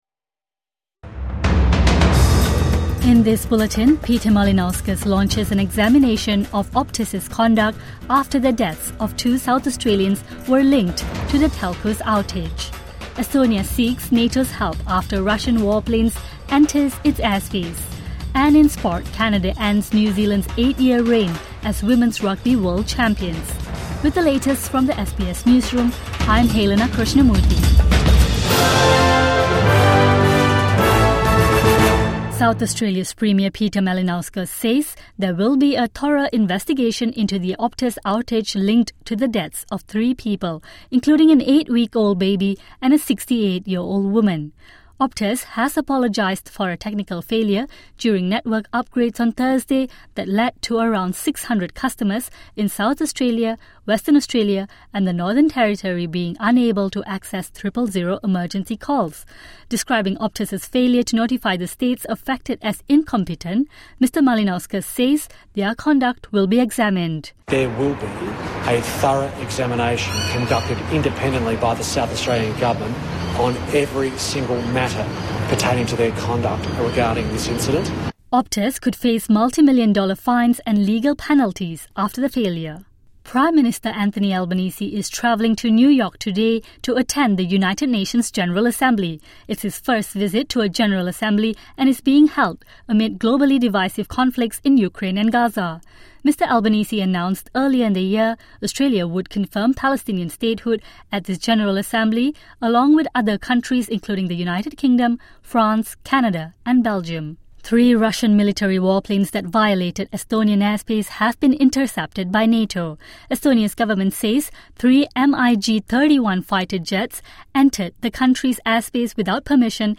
SA Premier launches an examination of Optus' conduct | Midday News Bulletin 20 September 2025